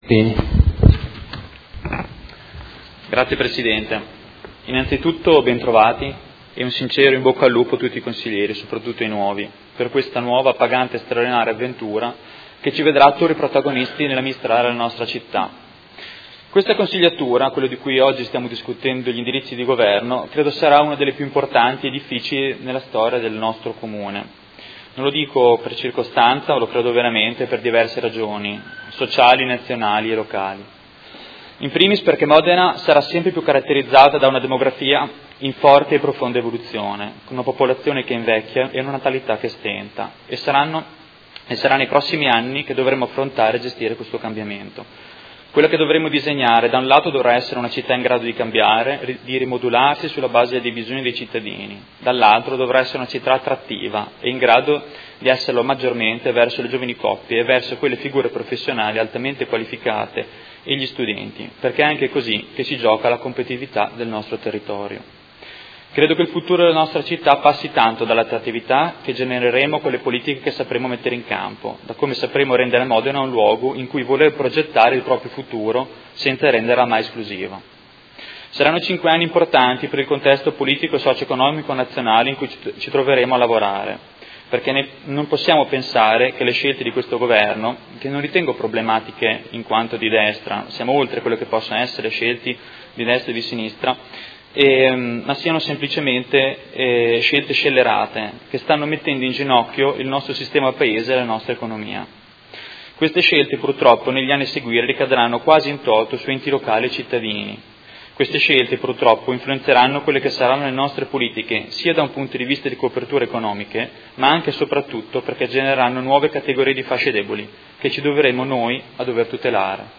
Seduta del 20/06/2019. Dibattito su proposta di deliberazione: Indirizzi Generali di Governo 2019-2024 - Discussione e votazione